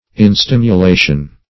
Instimulation \In*stim`u*la"tion\, n.